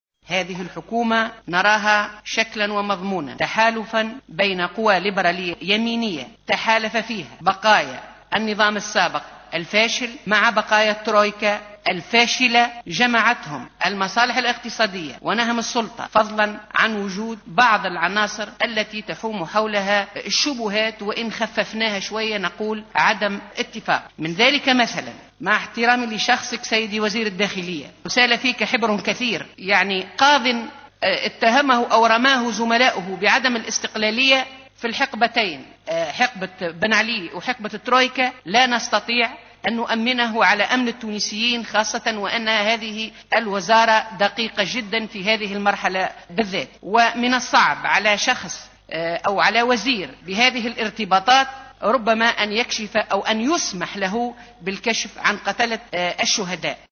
تتواصل ردود الفعل داخل قبة مجلس نواب الشعب بعد تقديم رئيس الحكومة المكلف الحبيب الصيد لتركيبة حكومته وبرنامجها حيث عبرت النائبة عن الجبهة الشعبية مباركة البراهمي في مداخلتها عن رفضها لترشيح ناجم الغرسلي على رأس وزارة الداخلية معلنة أنه من الصعب تأمينه على أمن التونسيين وفق قولها.